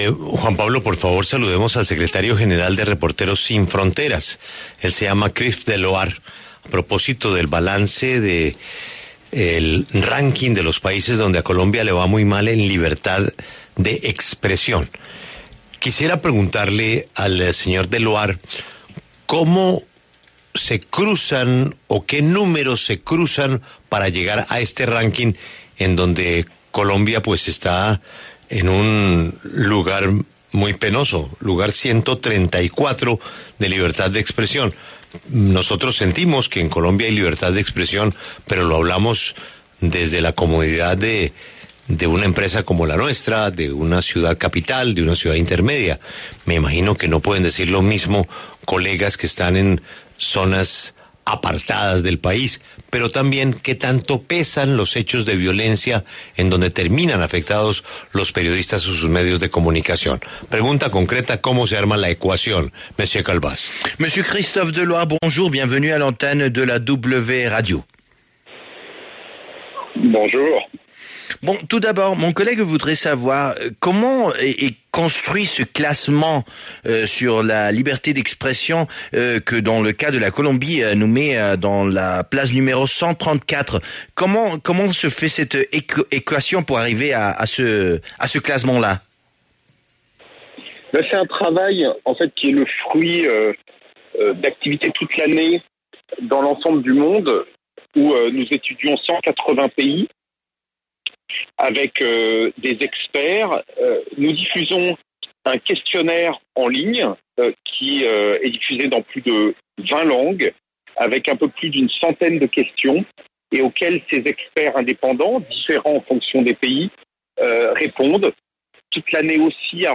Christophe Deloire, secretario General de Reporteros Sin Fronteras, habló en La W sobre la libertad de prensa en el mundo y los riesgos de ejercer el periodismo en países como China.